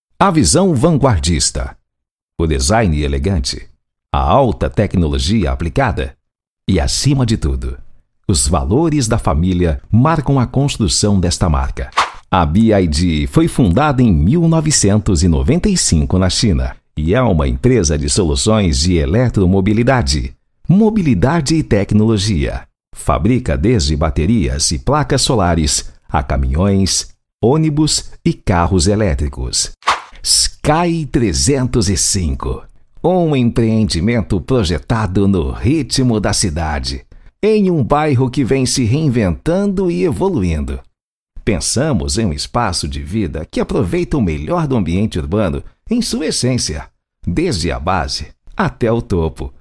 Demo Locução Padrão:
Padrão
Animada